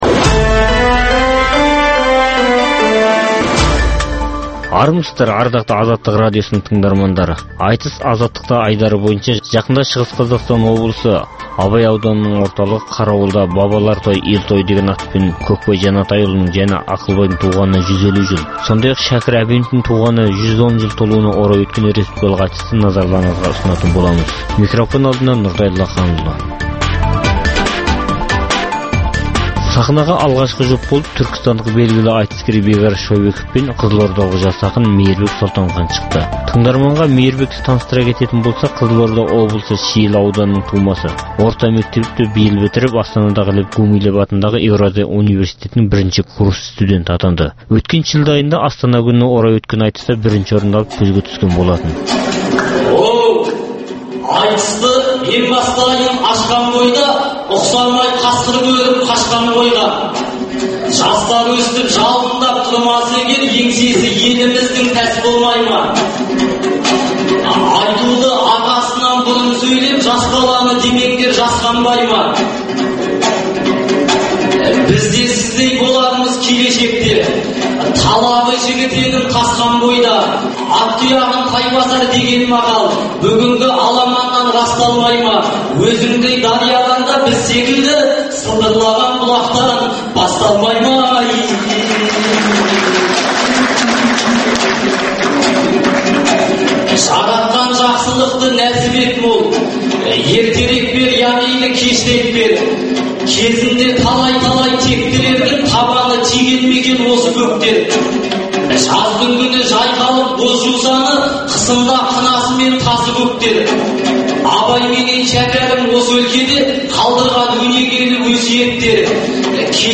Айтыс Азаттықта